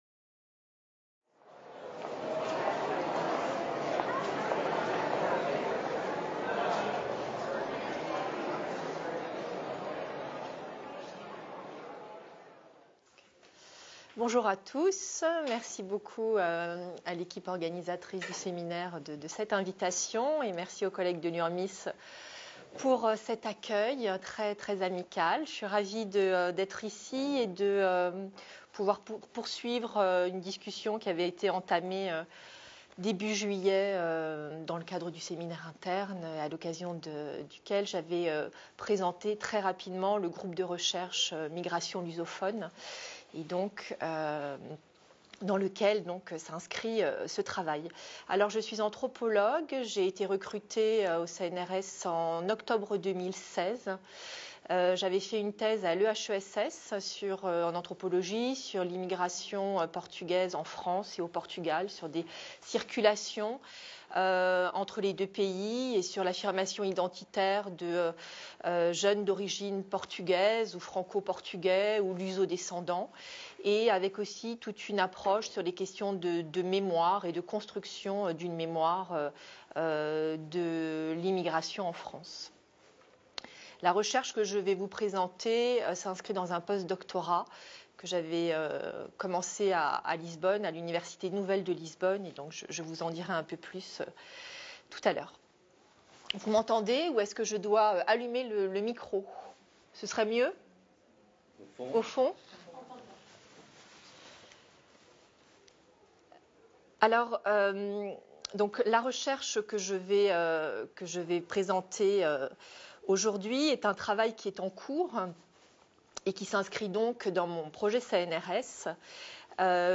Séminaire
Séminaire diffusé en streaming le vendredi 21 septembre depuis la MSHS de Nice.